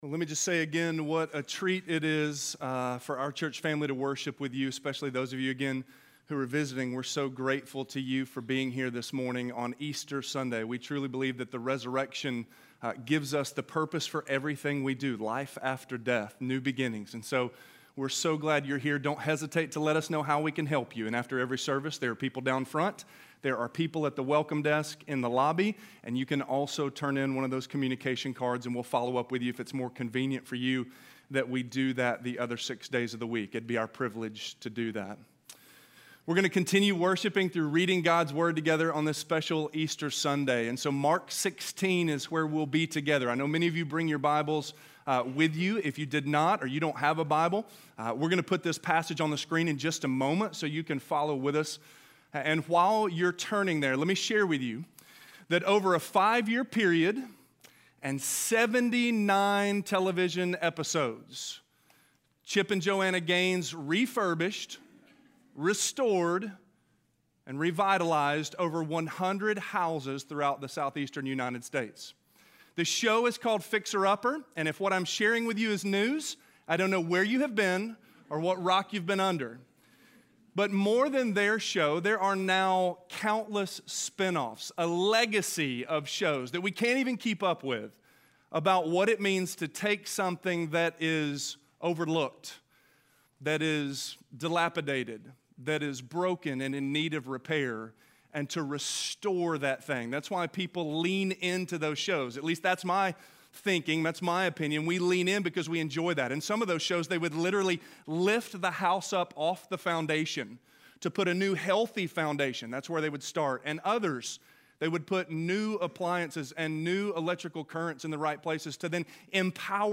Resurrection - Sermon - Avenue South